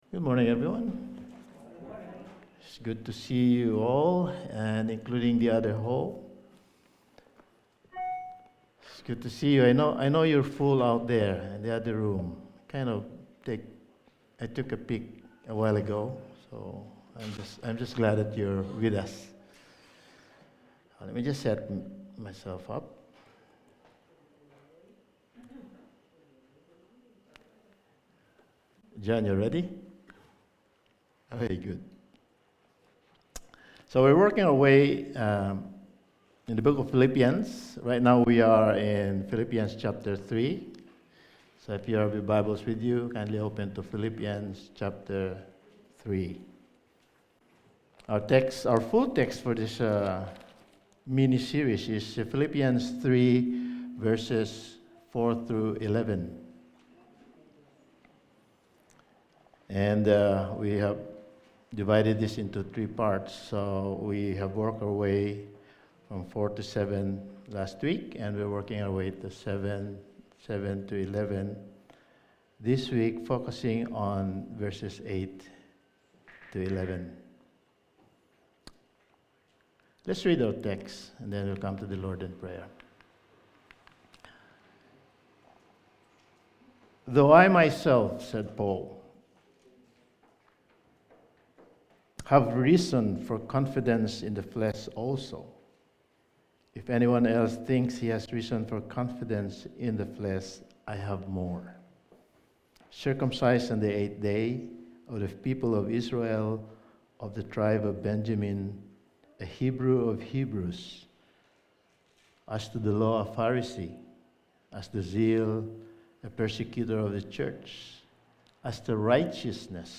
Passage: Philippians 3:7-11 Service Type: Sunday Morning